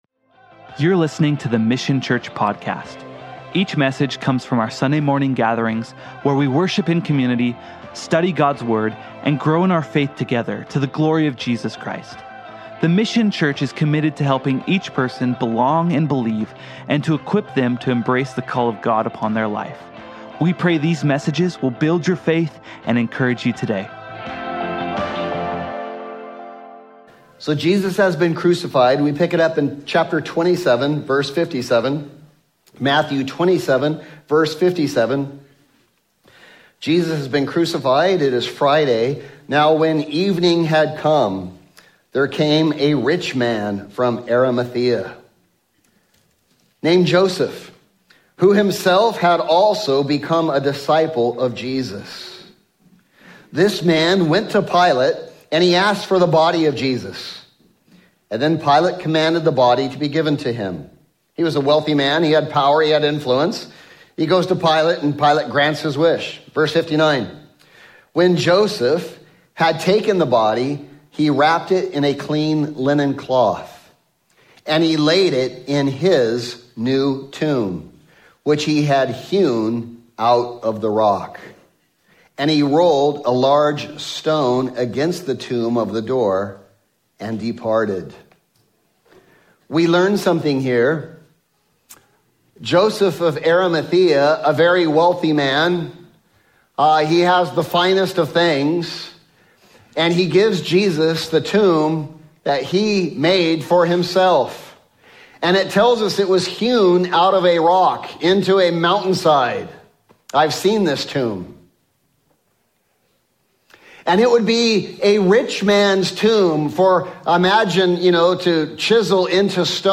Easter Sermon Series 2024
Matthew-27-The-Greatest-Miracle-Easter-Sunday.mp3